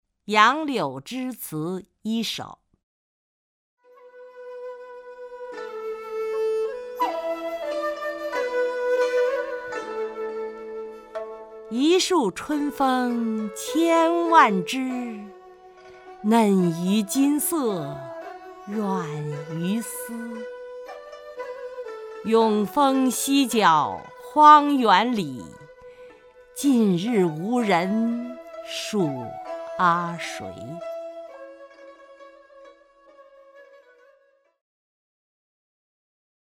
曹雷朗诵：《杨柳枝词》(（唐）白居易) （唐）白居易 名家朗诵欣赏曹雷 语文PLUS